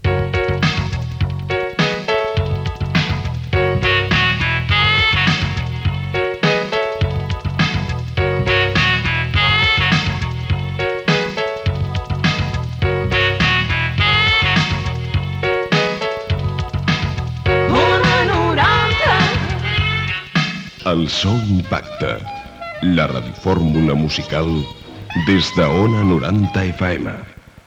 4c8f37baaec5326c45e8cbcffe9f9d421d9c3fe1.mp3 Títol Ona 90 de Ràdio Emissora Ona 90 de Ràdio Titularitat Pública municipal Descripció Indicatiu emissora i de la radiofórmula "El so impacte".